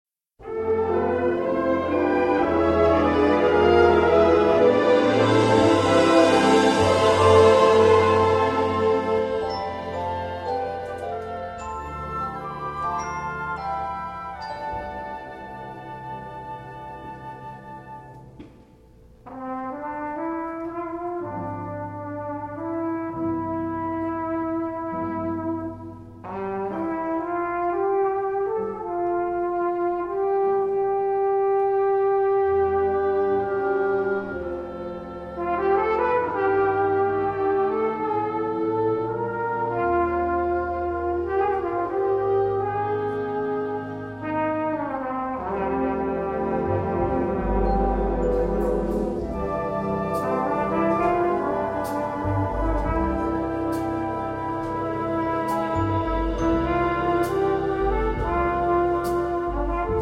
Flugelhorn